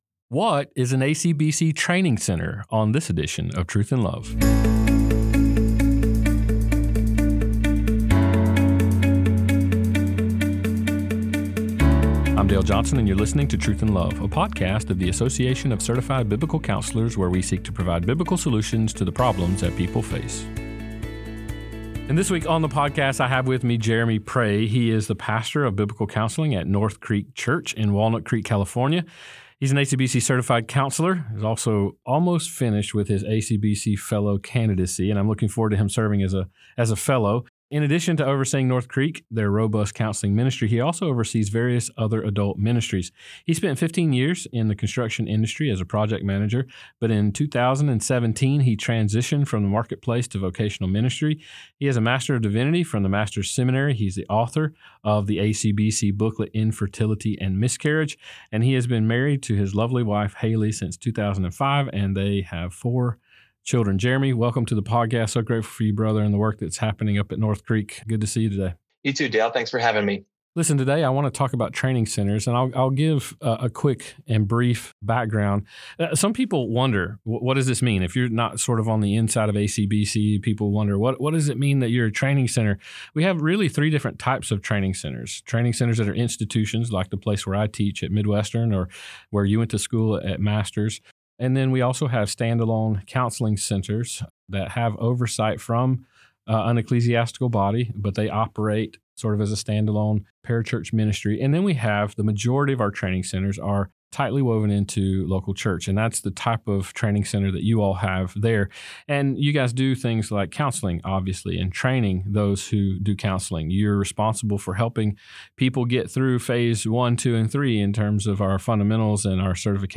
As March Madness heats up, we jump into an inspiring conversation with former WNBA player